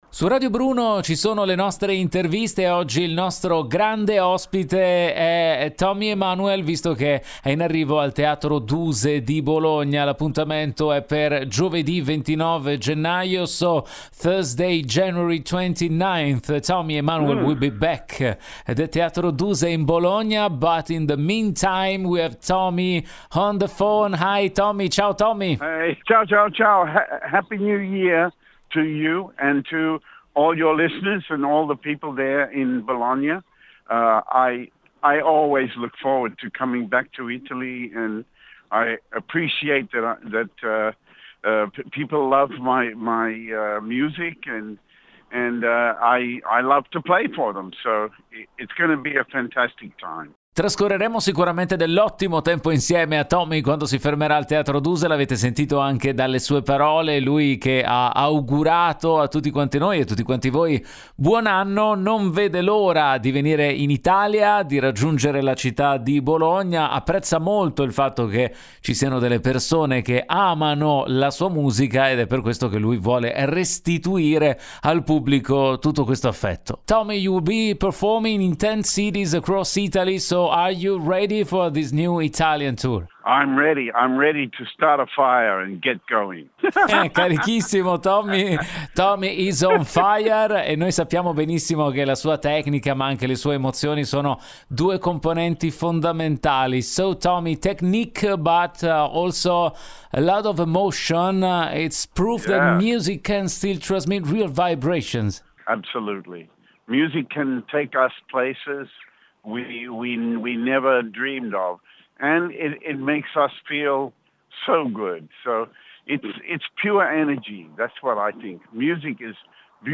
Home Magazine Interviste Tommy Emmanuel in concerto a Bologna